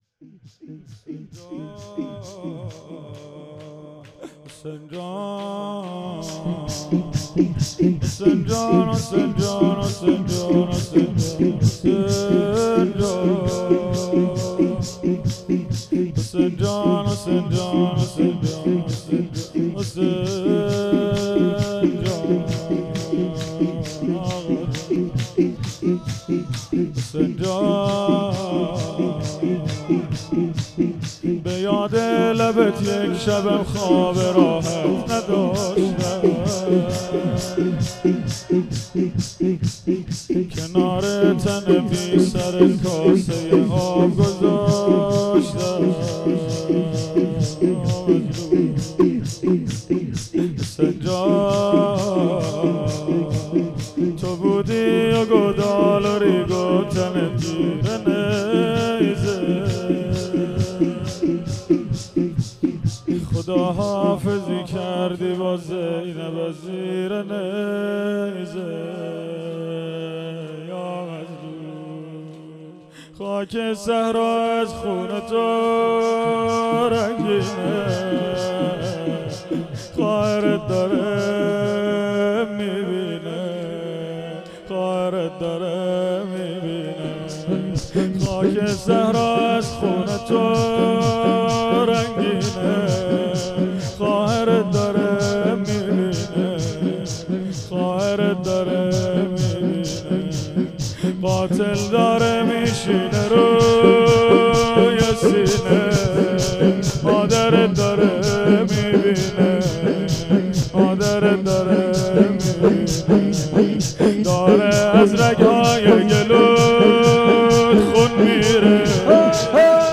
هیئت حسن جان(ع) اهواز - شور روضه ای | حسین جان
دهه اول محرم الحرام ۱۴۴۴